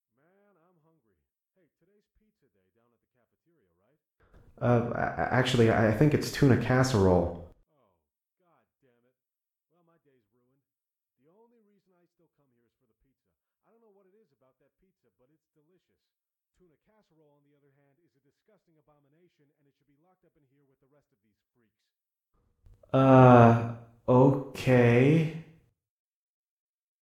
Conversation3b.ogg